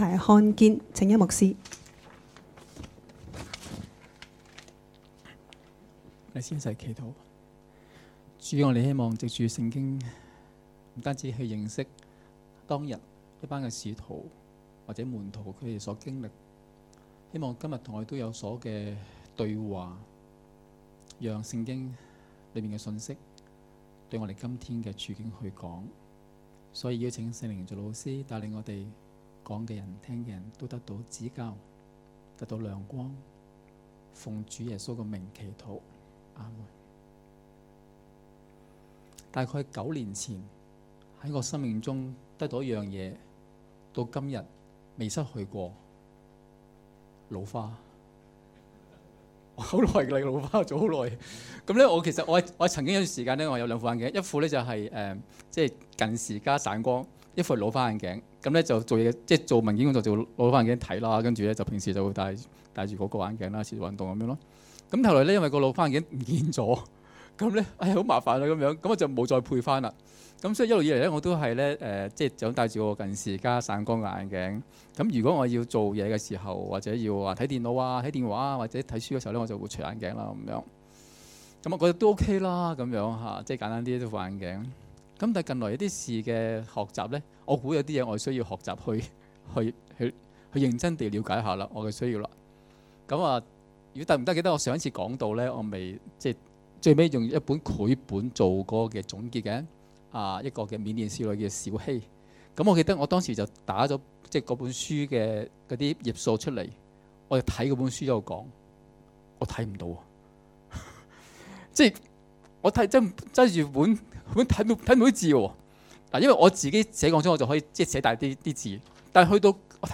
2018年5月26日及27日崇拜講道